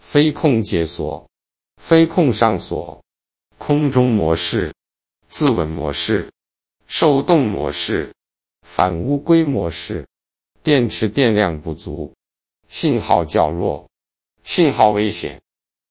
语音包